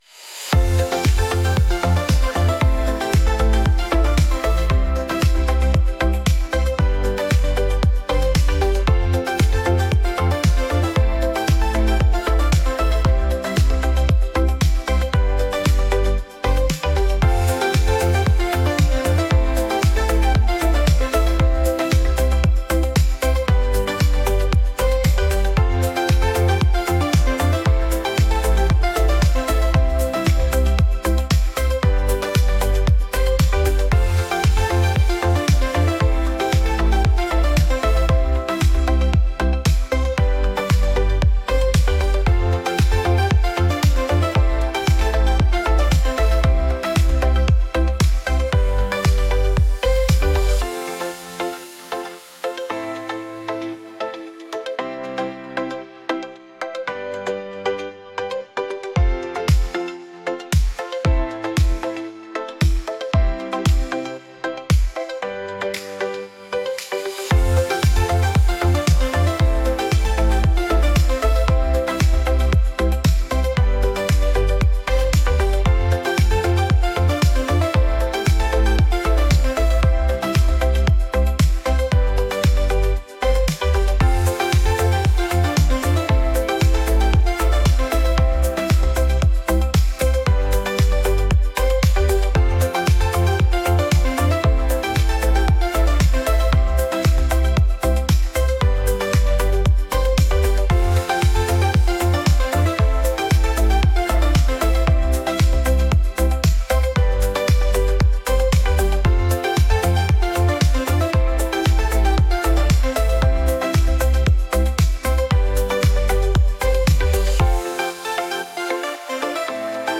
catchy | pop